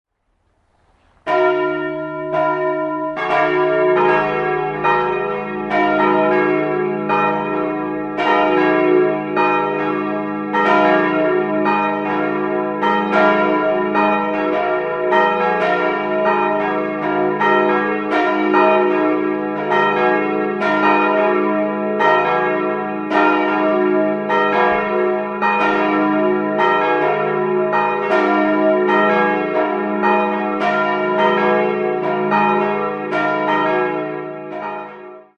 Der Chorturm ist älteren Ursprungs, während das Langhaus ab 1719 neu erbaut wurde. 3-stimmiges TeDeum-Geläute: dis'-fis'-gis' Die drei Gussstahlglocken aus Bochum stammen aus dem Jahr 1948. Exakte Schlagtöne: dis'+2, fis'+2, gis'-1